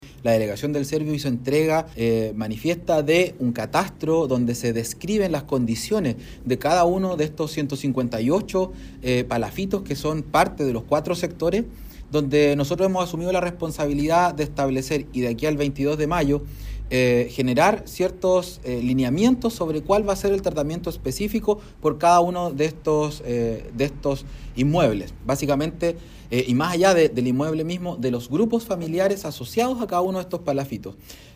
Tras la reunión que sostuvieron las autoridades con dirigentes del sector, el seremi de Vivienda, Fabián Nail, explicó que en el catastro se describen las condiciones en que están estas estructuras de madera y anunció que, en la próxima reunión del 22 de mayo, presentarán los lineamientos de los tratamientos que se dará a cada uno de los inmuebles y sus grupos familiares.